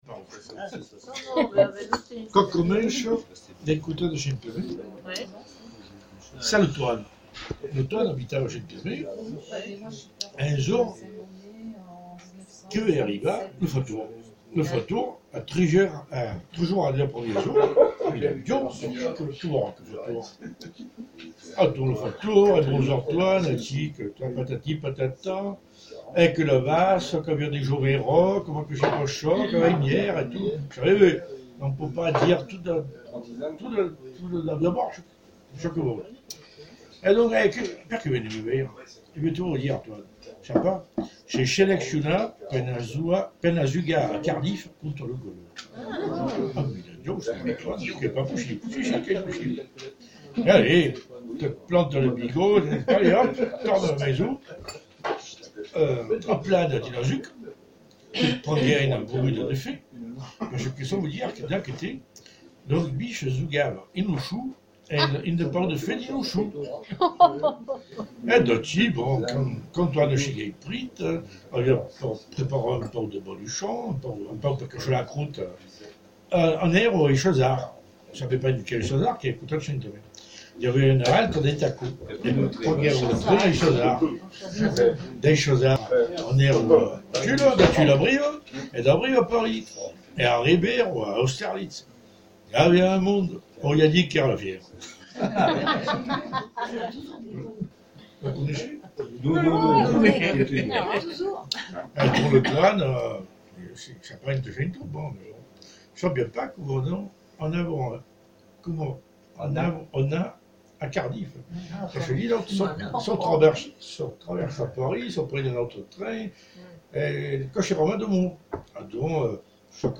se réunit tous les deux ou trois mois dans un café de TULLE
Peu d'enregistrements de qualité
car le lieu est très bruyant !
Noel  traditionnel   La chançon daus mes